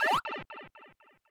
Sound effect of Pipe Slide Enter (World Map) (Alternative) in Super Mario Bros. Wonder
SMBW_Pipe_Slide_Enter_World_Map_2.oga